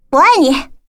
女生直爽说我爱你音效免费音频素材下载